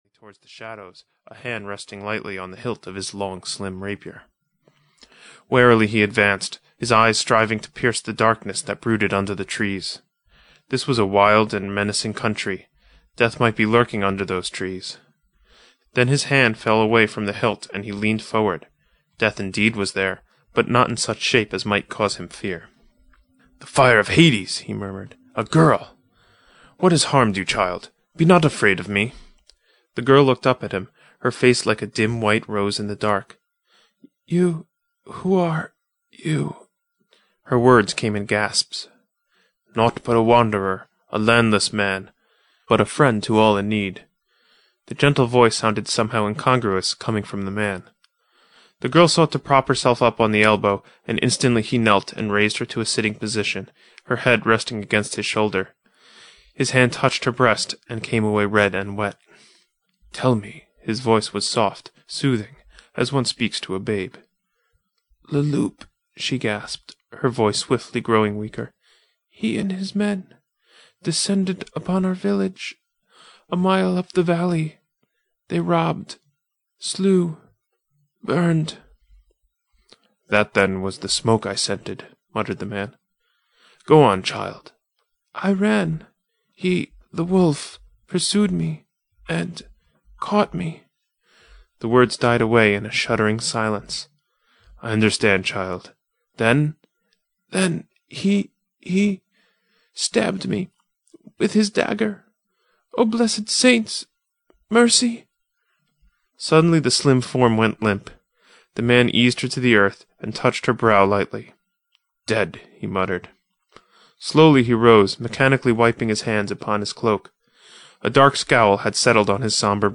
Red Shadows (EN) audiokniha
Ukázka z knihy